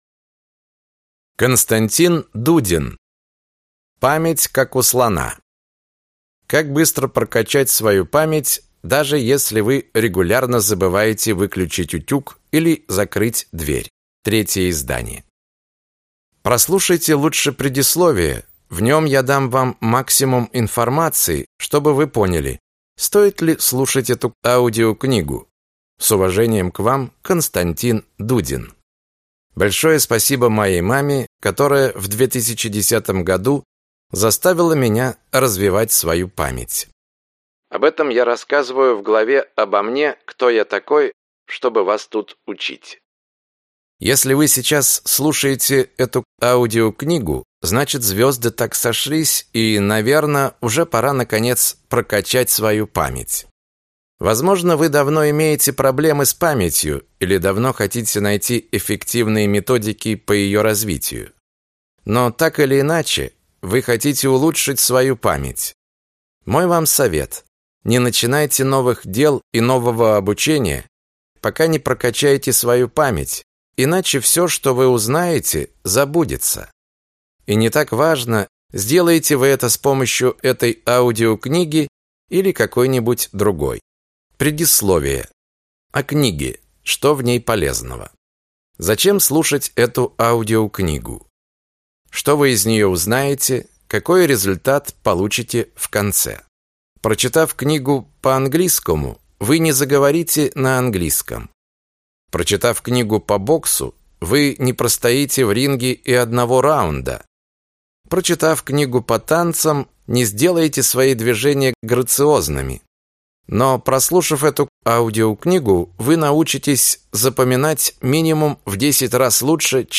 Аудиокнига Память, как у слона. Как быстро прокачать свою память, даже если вы регулярно забываете выключить утюг или закрыть дверь | Библиотека аудиокниг